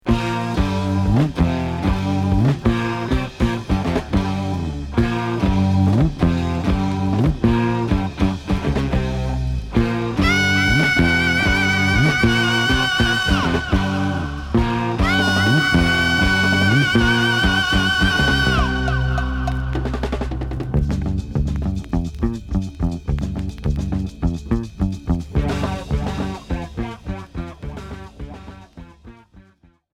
Heavy rock pop Troisième 45t retour à l'accueil